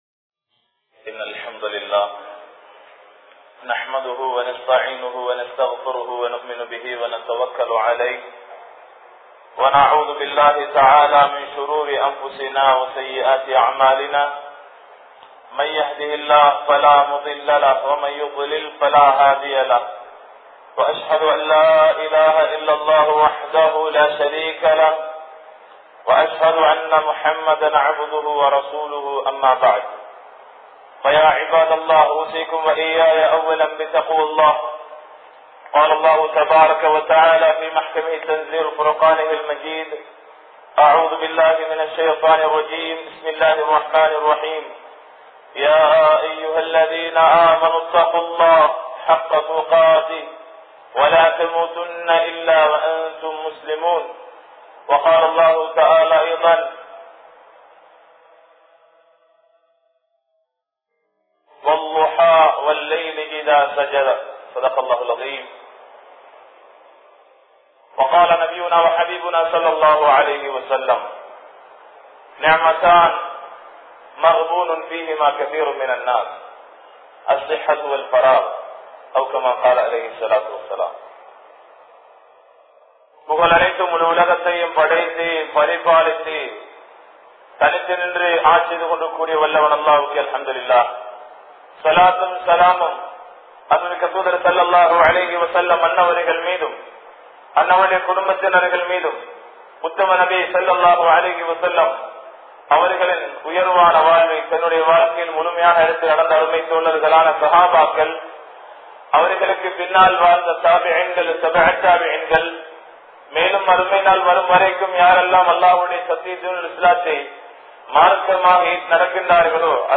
Nerathin Perumathi (நேரத்தின் பெறுமதி) | Audio Bayans | All Ceylon Muslim Youth Community | Addalaichenai